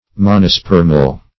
Meaning of monospermal. monospermal synonyms, pronunciation, spelling and more from Free Dictionary.
Search Result for " monospermal" : The Collaborative International Dictionary of English v.0.48: Monospermal \Mon`o*sper"mal\, Monospermous \Mon`o*sper"mous\, a. [Mono- + Gr. spe`rma seed: cf. F. monosperme.]